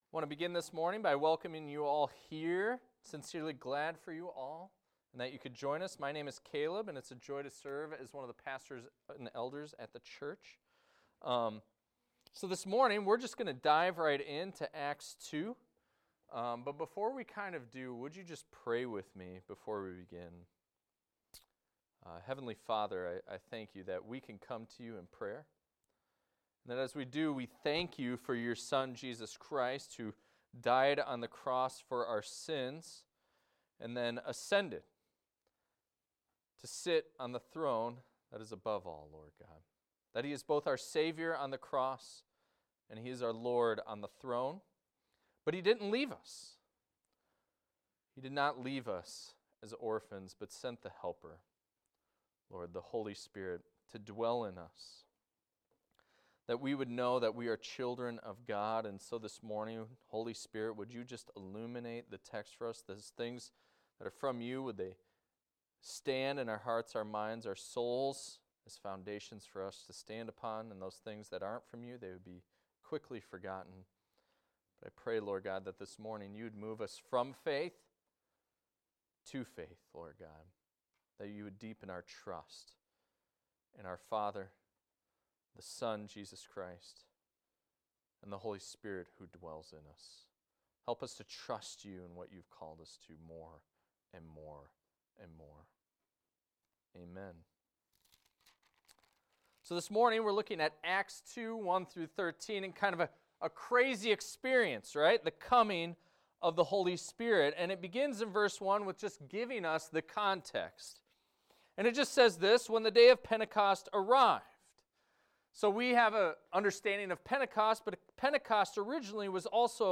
This is a recording of a sermon titled, "Filled With The Spirit."